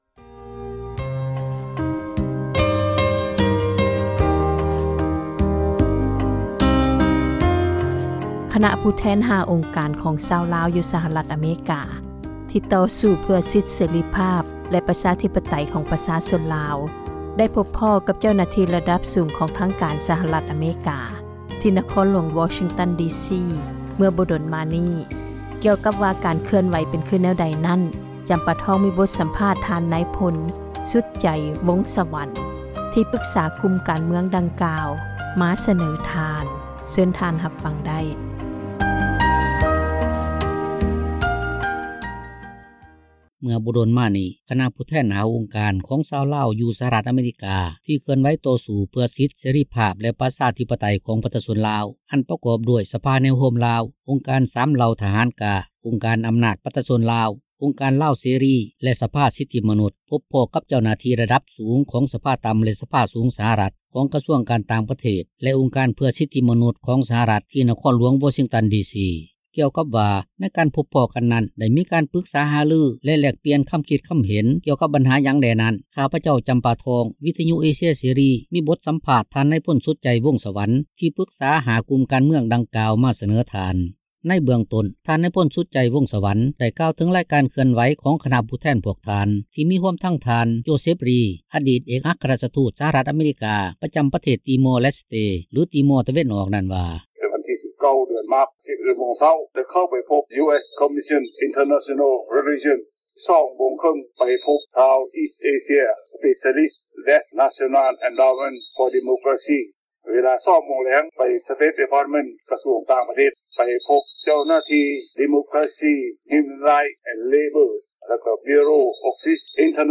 ເສຣີພາບ ແລະ ປະຊາທິປະໄຕ ຂອງປະຊາຊົນລາວ ຊຶ່ງມີຂຶ້ນຢູ່ວັດລາວພຸທວົງ ທີ່ເມືອງ ແກັດແລັດ ຣັຖເວີຈິເນັຍ ໃນຂົງເຂດ ນະຄອນຫຼວງ ວໍຊິງຕັນ ດີຊີ ໃນຕອນເຊົ້າ ຂອງມື້ວັນທີ 6 ພຶສພາ 2018.